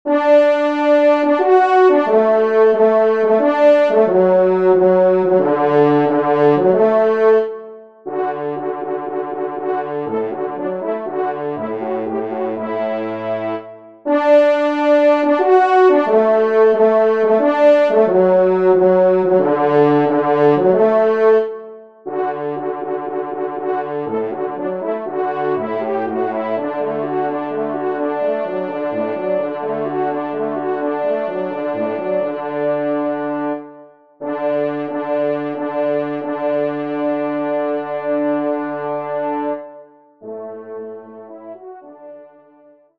Genre : Divertissement pour Trompes ou Cors
Trompe 1  (en exergue)